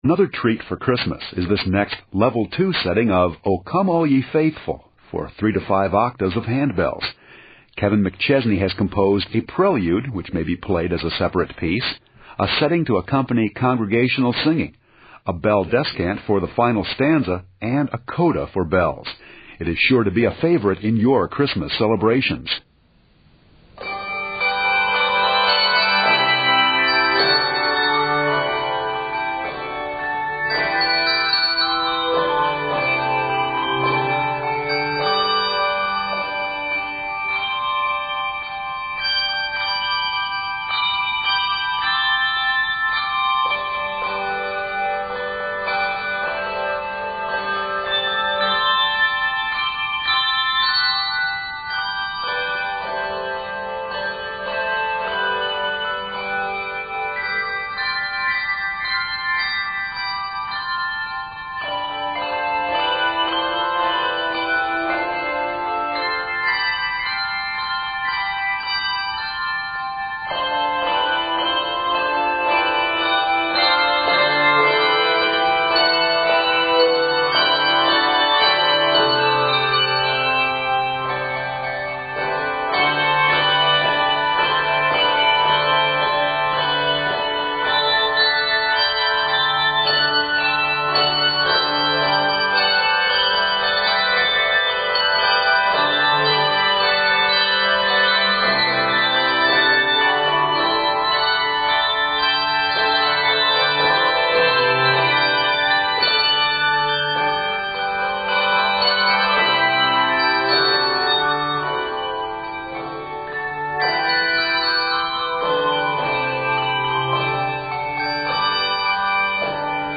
Or you can do the entire arrangement as a bell score.
Octaves: 3-5